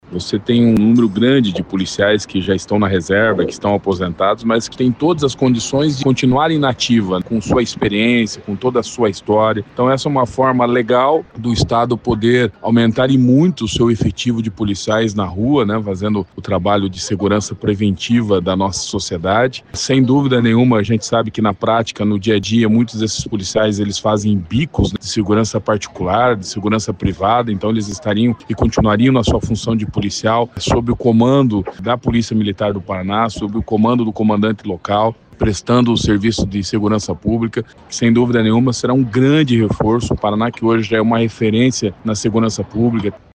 O deputado estadual Gugu Bueno, primeiro-secretário da Assembleia Legislativa do Paraná, disse que a proposta aprovada representa um avanço nas questões relacionadas à segurança pública.